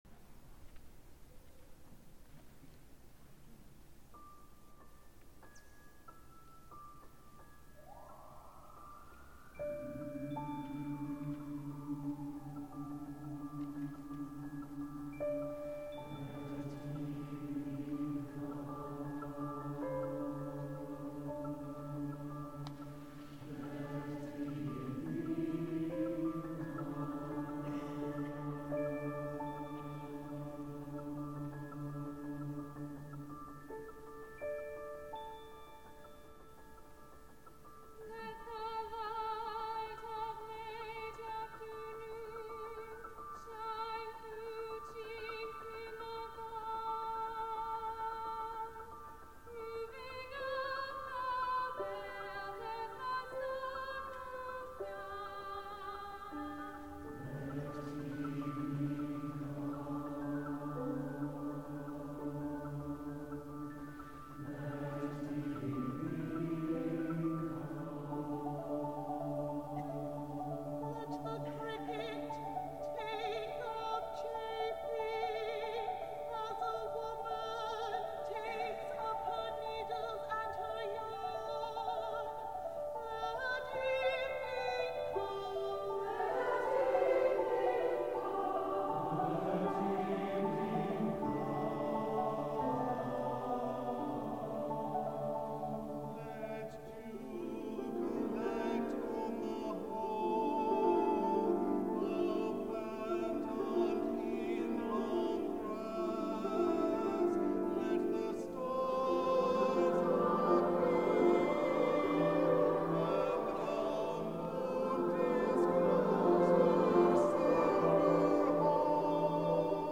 for SATB Chorus, Opt. Percussion, and Piano (2006)